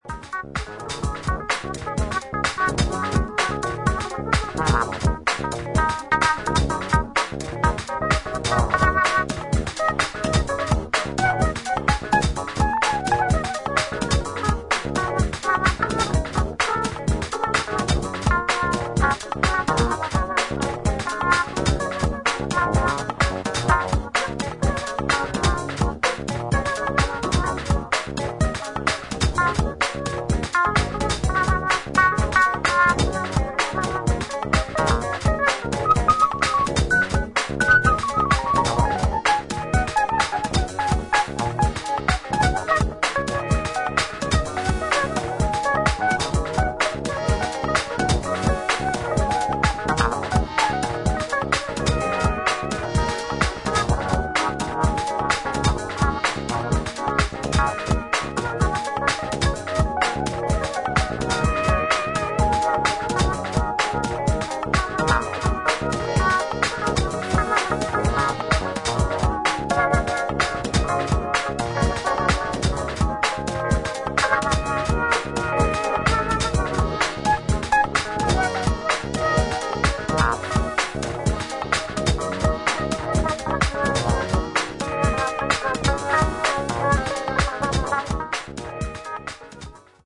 有機的な音と電子音の印象的な融合をハウスの領域で表現した一枚